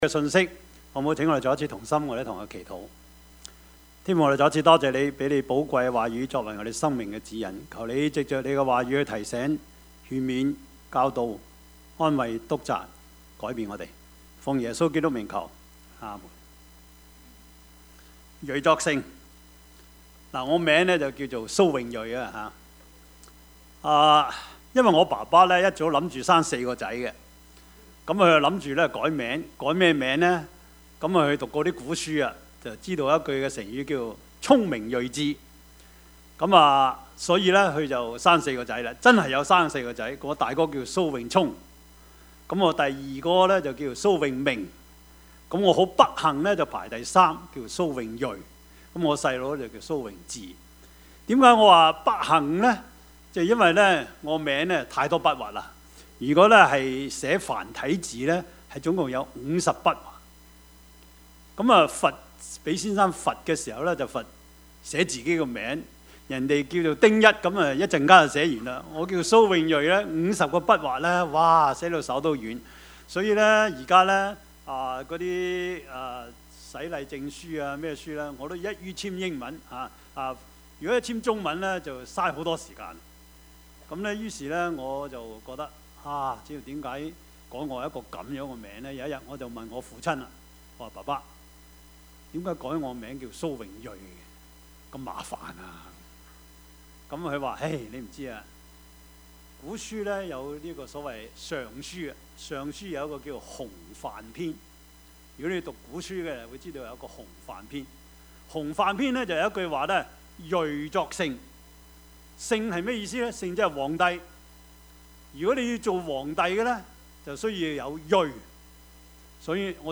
傳 8:1-9 Service Type: 主日崇拜 Bible Text
Topics: 主日證道 « 要我給你們做甚麼?